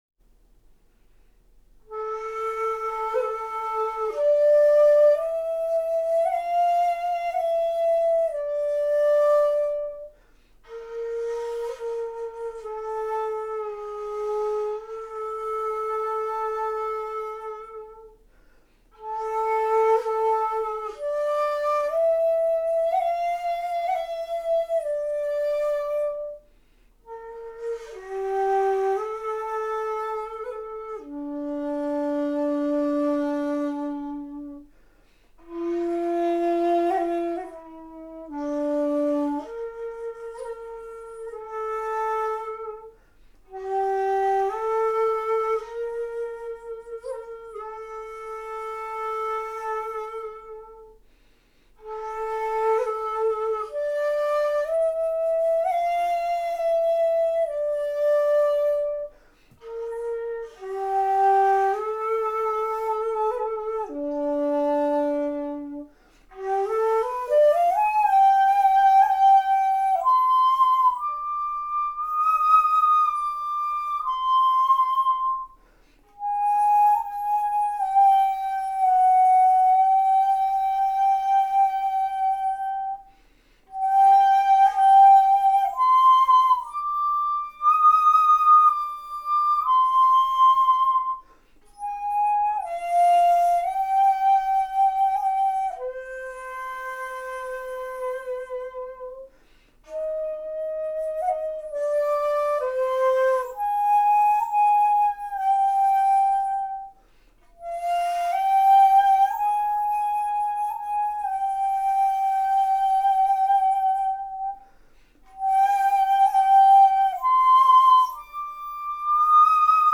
ただ参考までに「荒城の月」については好きな曲ですので一度吹いてみました。
（課題曲楽譜通り」音源）